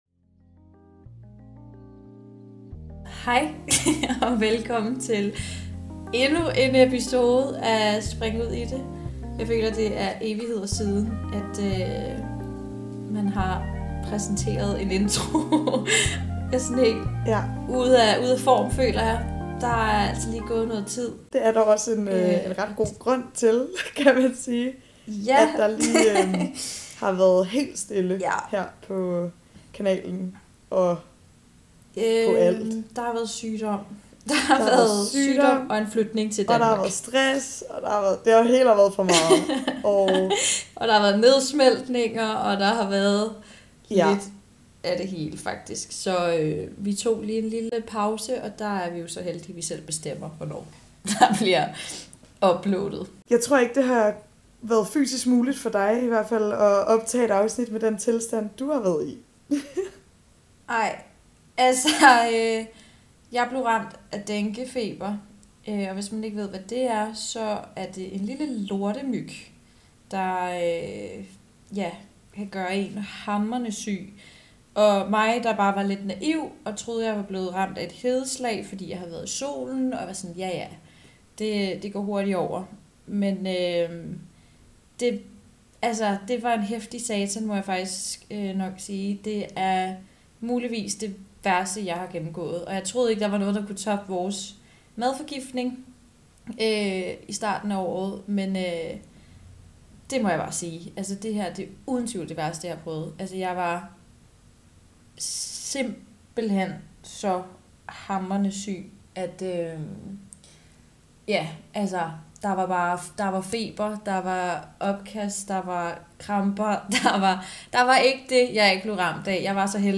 Det er en ærlig og sårbar snak om livets op- og nedture.